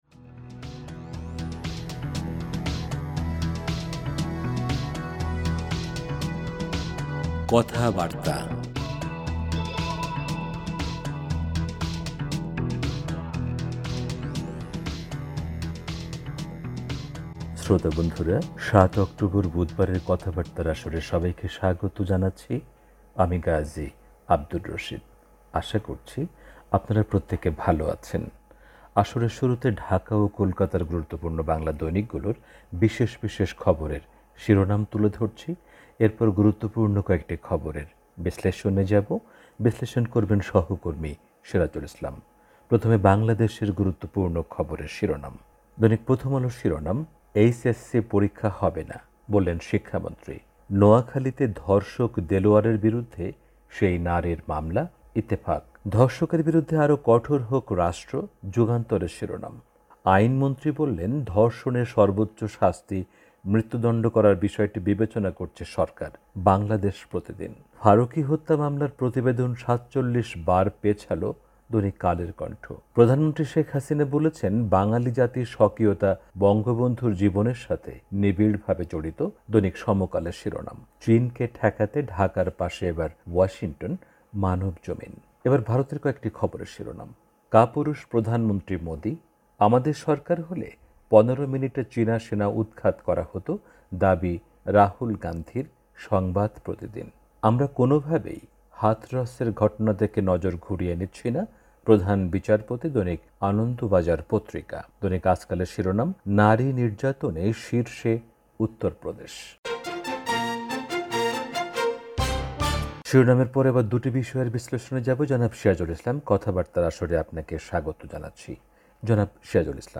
রেডিও